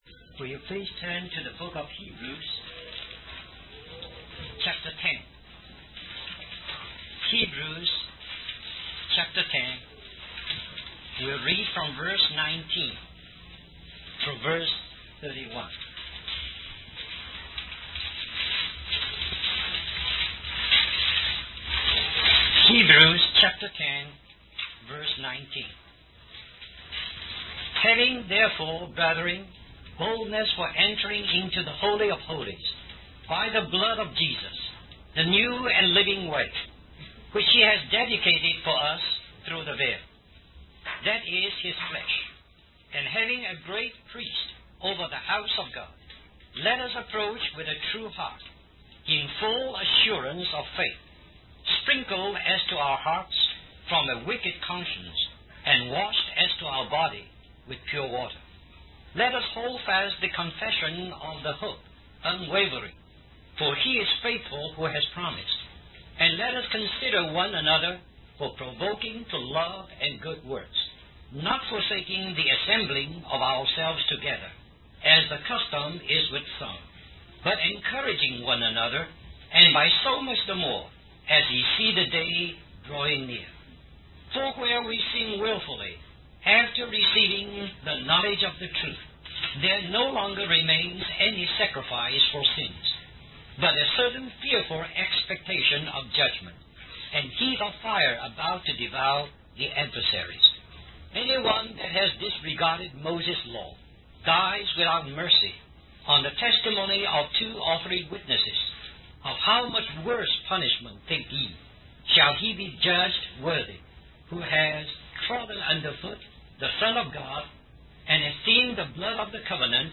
The video is a sermon on the book of Hebrews, which is described as a letter of exhortation. The speaker emphasizes the importance of salvation and encourages the audience to pay special attention to what they have heard. The sermon also focuses on the heavenly calling of believers, highlighting their role as the house of God and companions of Christ.